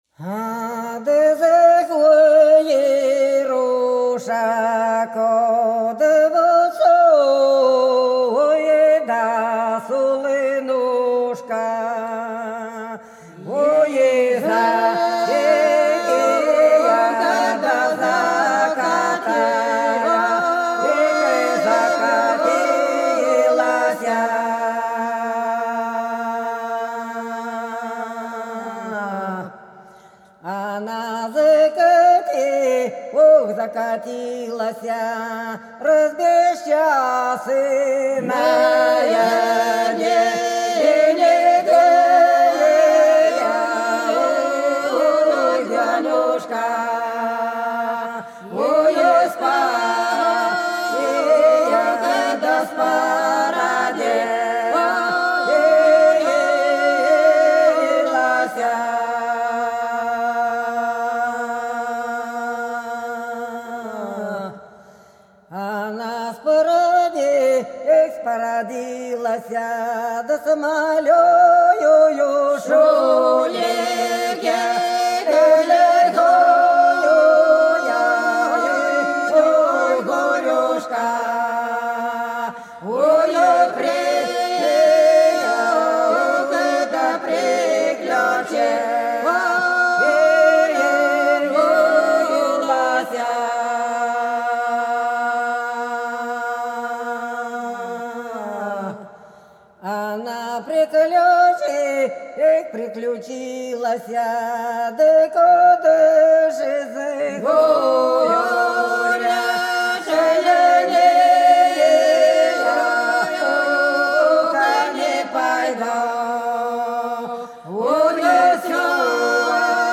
Белгородские поля (Поют народные исполнители села Прудки Красногвардейского района Белгородской области) Ой, да за горушку солнышко закатилось - протяжная